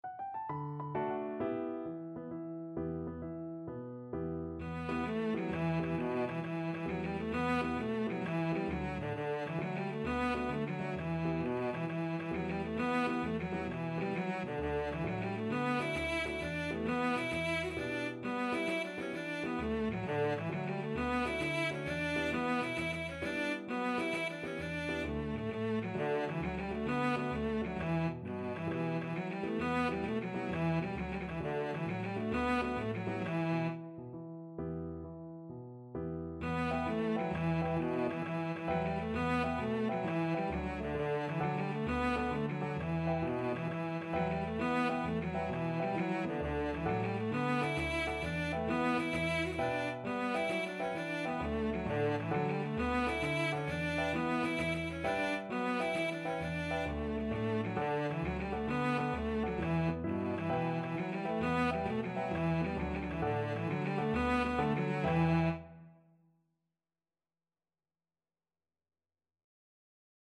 Free Sheet music for Cello
Cello
E minor (Sounding Pitch) (View more E minor Music for Cello )
9/8 (View more 9/8 Music)
Irish